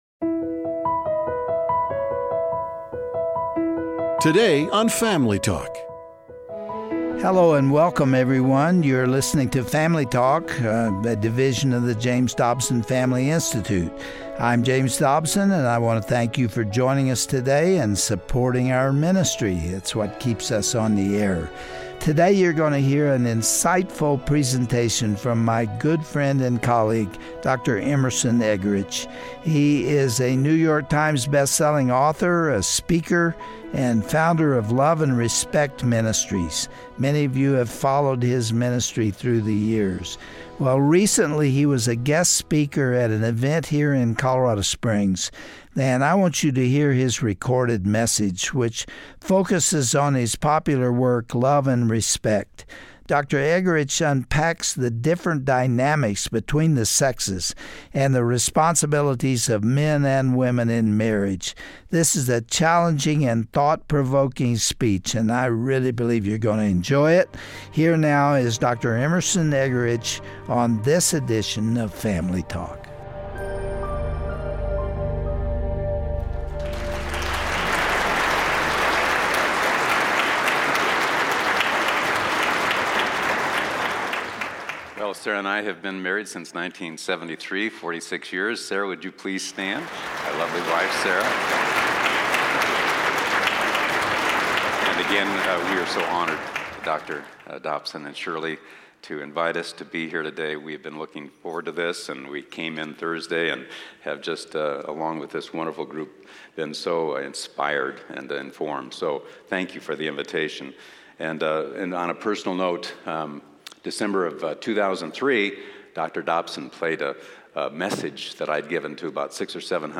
The welfare of every marriage hinges on the mutual admiration and adoration husbands and wives have for one another. On todays Family Talkbroadcast